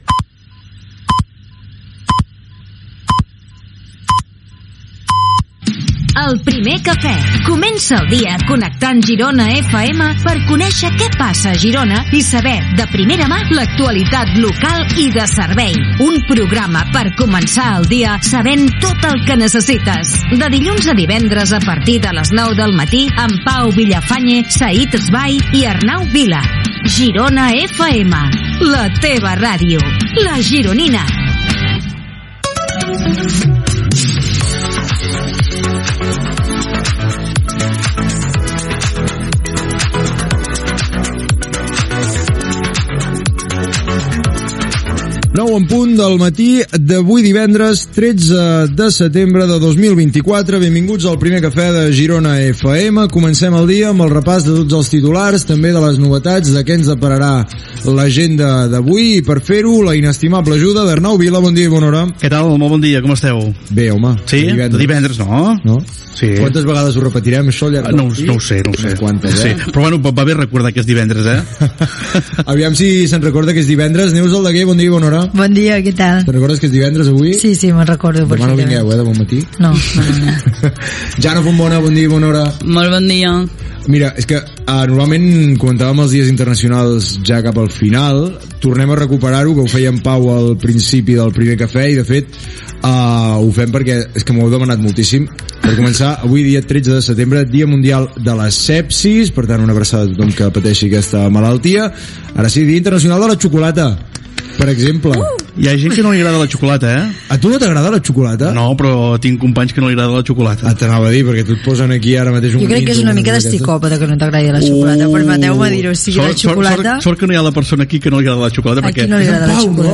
Senyals horaris, careta, presentació de l'equip, el dia internacional de la xocolata, i altres dies internacionals
Entreteniment